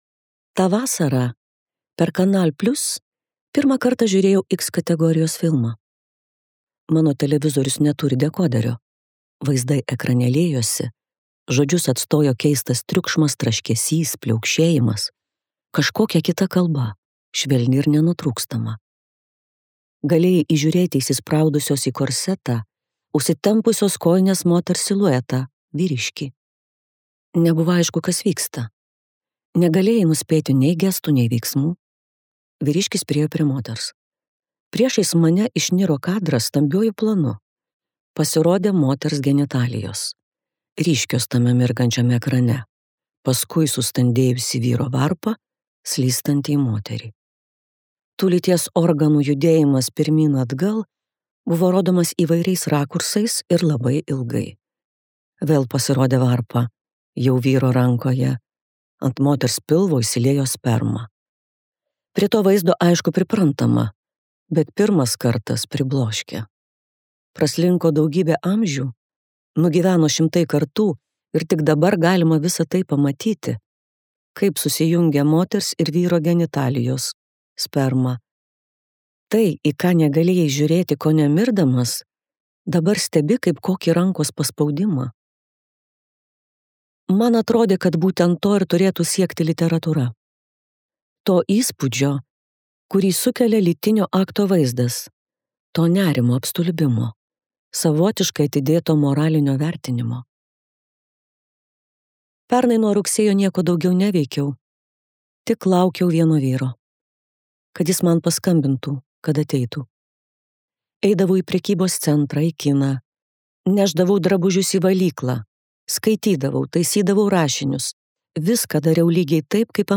Annie Ernaux audioknyga „Paprasta aistra“ atgimsta Jolantos Dapkūnaitės balsu. Tai neilgas pasakojimas apie tai, kokia įtraukianti, visa užgožianti ir nuginkluojanti gali būti meilė vyrui.
Balsas: Jolanta Dapkūnaitė